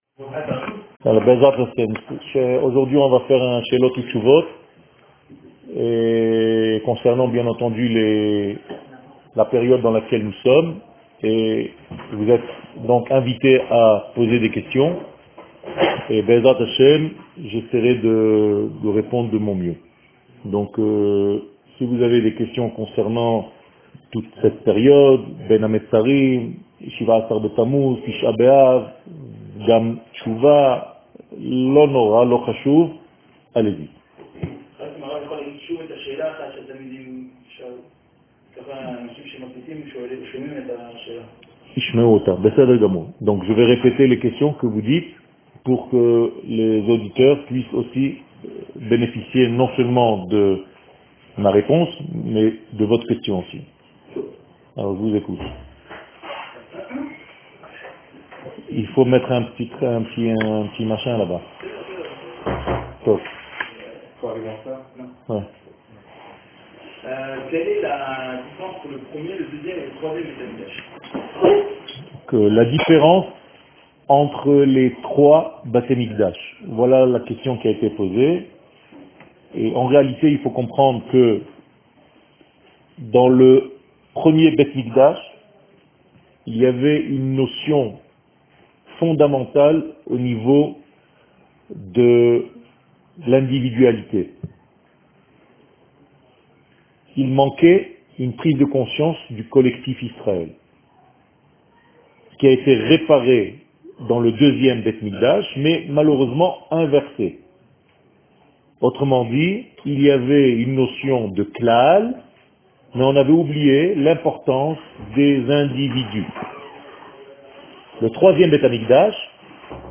Questions---Reponses---Machon-Meir.m4a